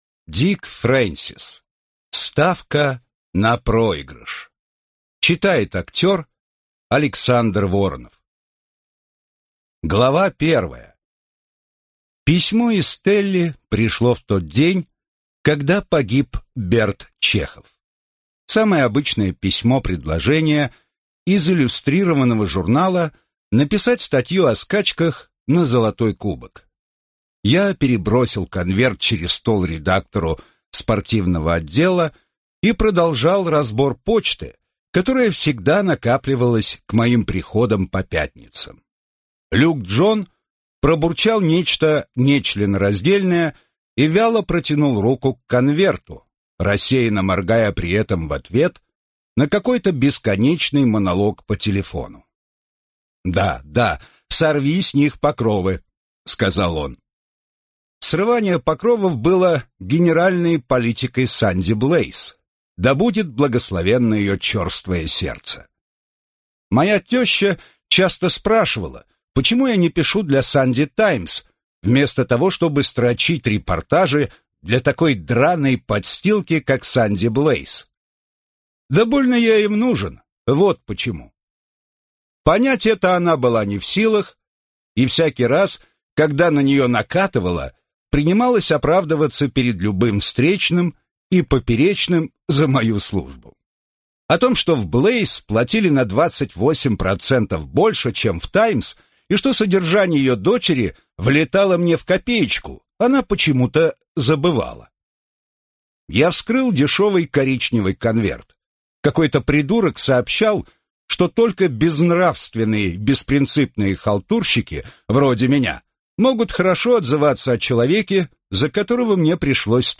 Аудиокнига Ставка на проигрыш | Библиотека аудиокниг